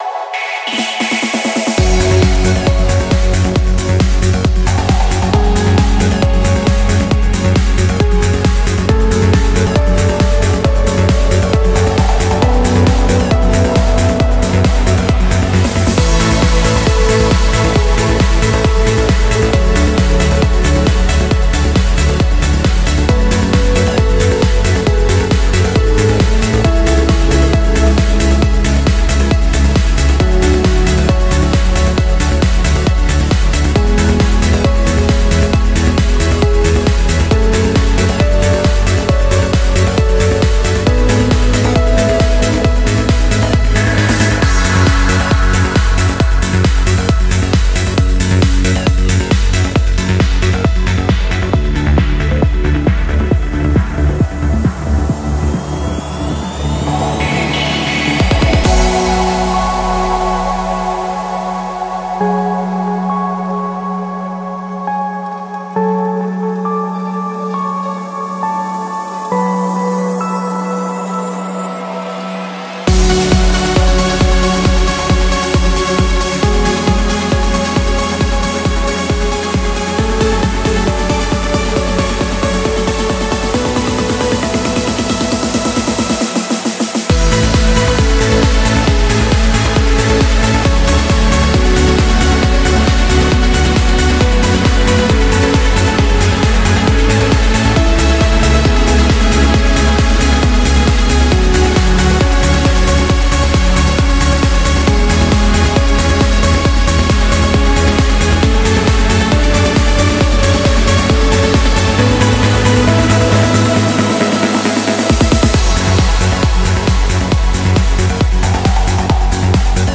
BPM34-135
CommentsNEW MILLENNIUM TRANCE